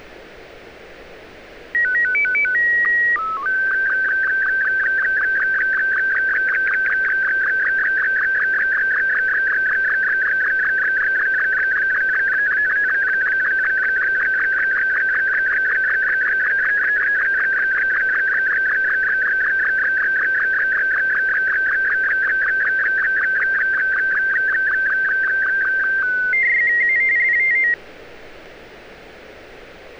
SSTV.wav